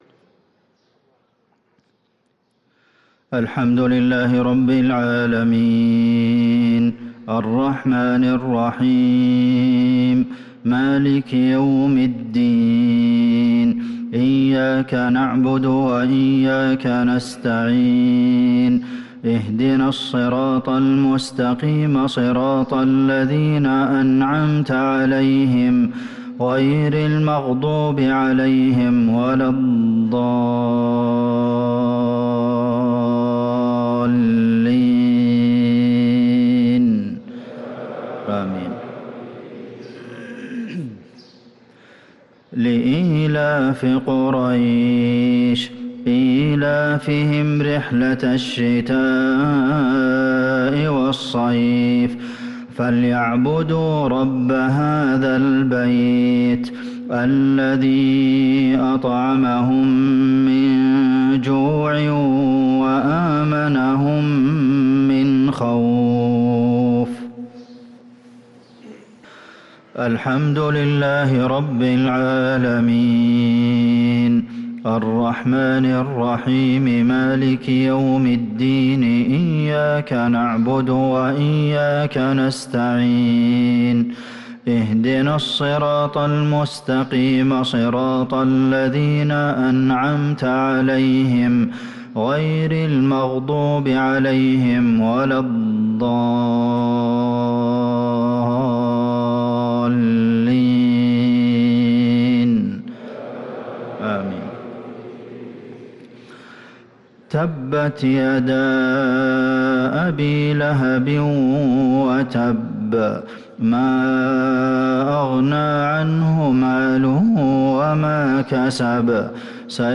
صلاة المغرب للقارئ عبدالمحسن القاسم 2 رجب 1445 هـ
تِلَاوَات الْحَرَمَيْن .